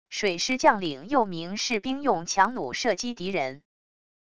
水师将领又名士兵用强弩射击敌人wav音频